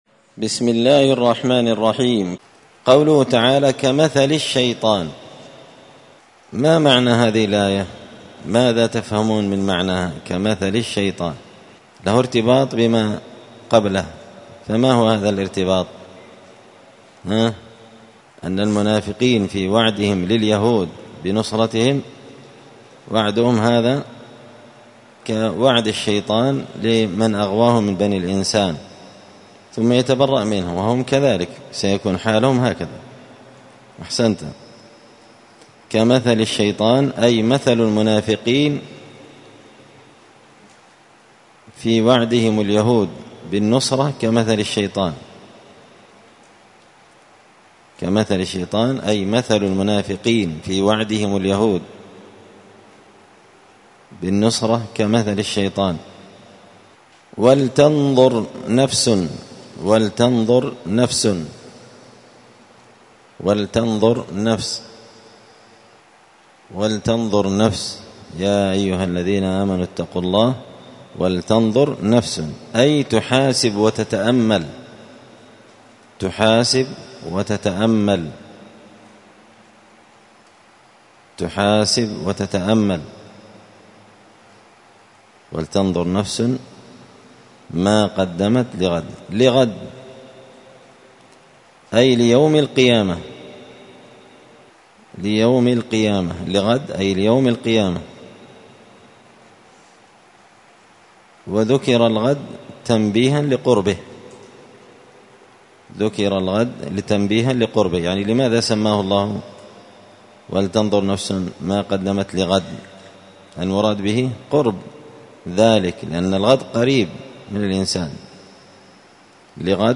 مسجد الفرقان قشن_المهرة_اليمن 📌الدروس اليومية